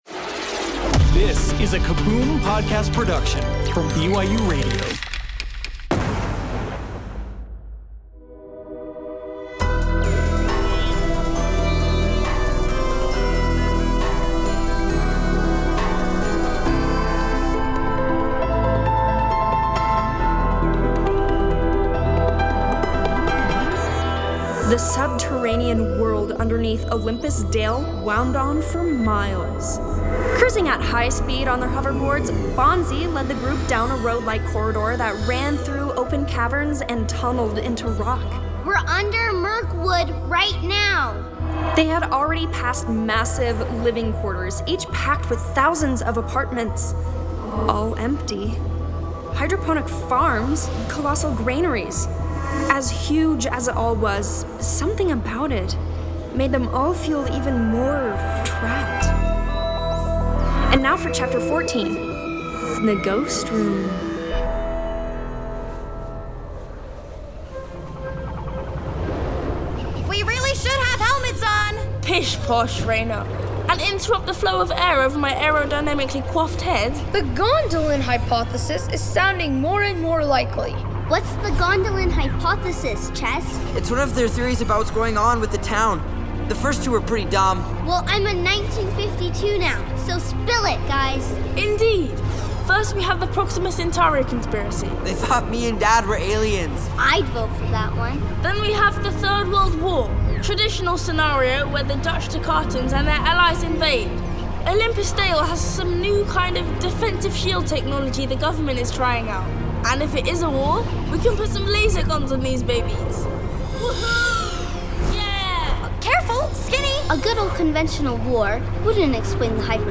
Bedtime Stories Audio Drama BYUradio Sci-Fi / Fantasy Stories Content provided by BYUradio.